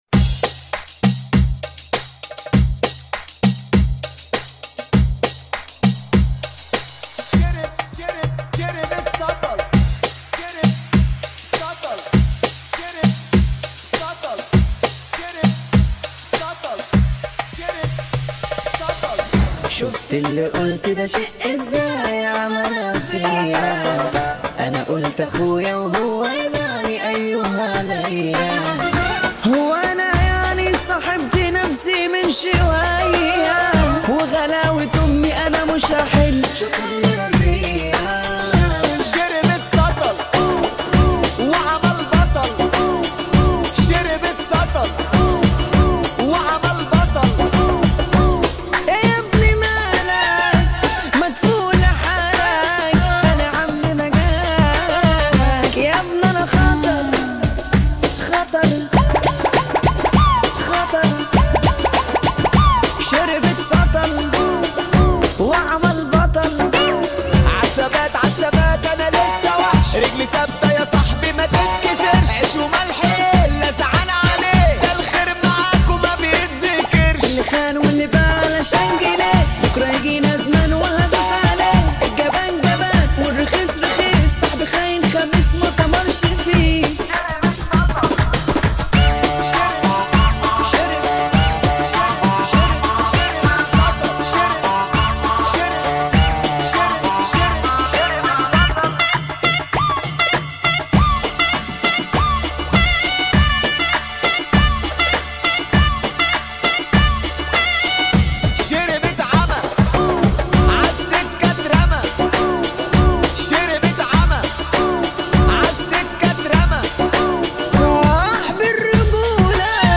[ 100 bpm ] 2022 - مهرجان مصري